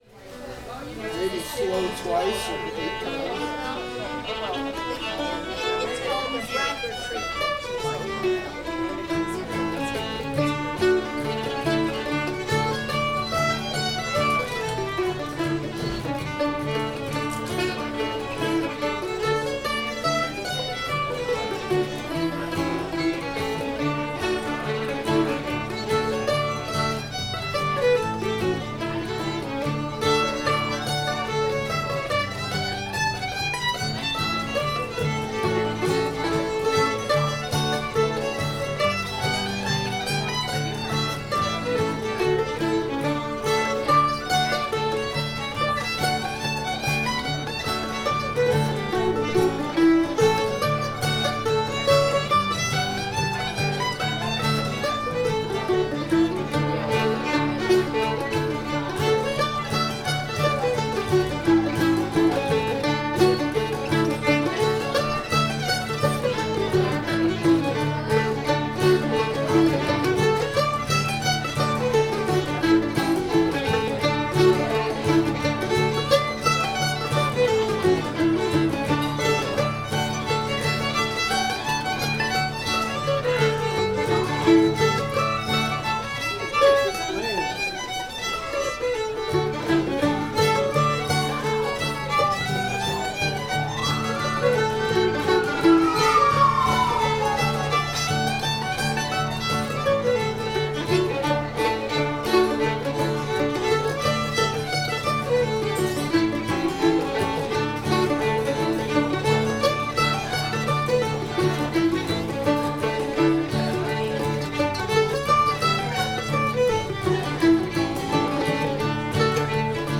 old virginia reel [D]